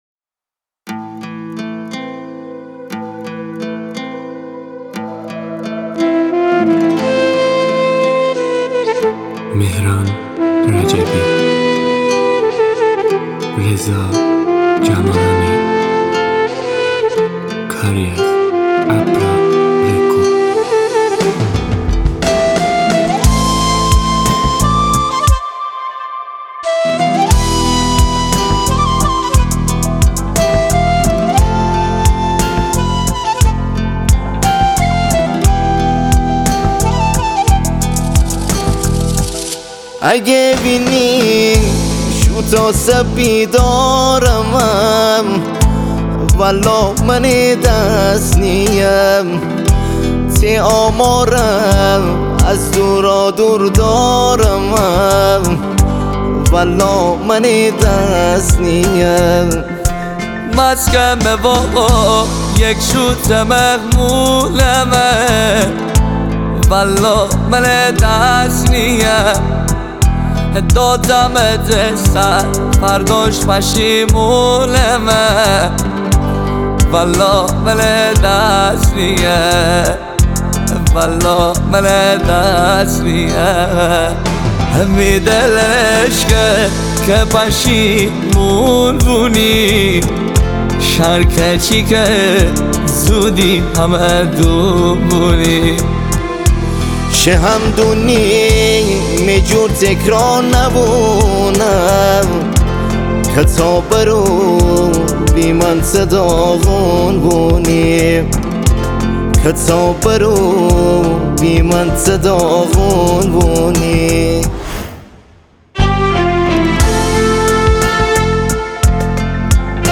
غمگین
آهنگ غمگین مازندرانی